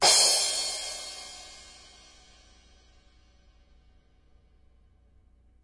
混合镲片 " Crash2
我相信话筒是AKG 414。在录音室环境中录制的。